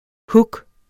Udtale [ ˈhug ]